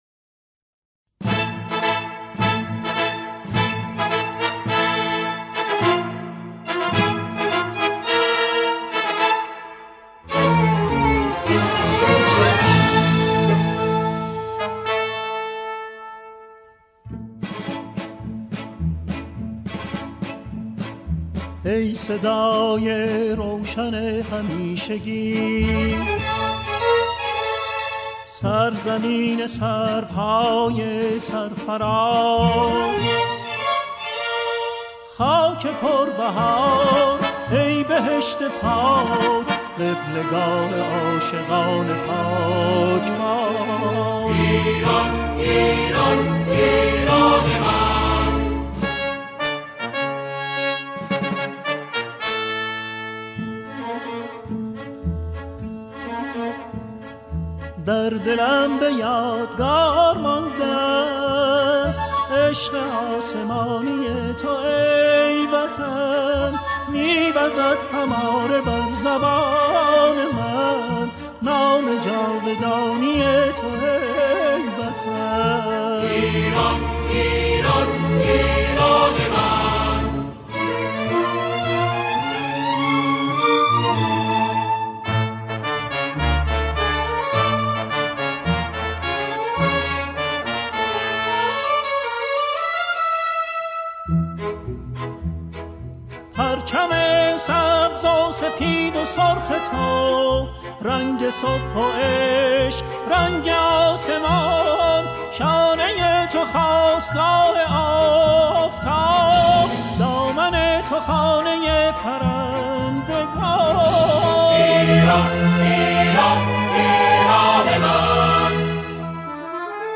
در این قطعه، او، شعری را با موضوع ایران همخوانی می‌کند.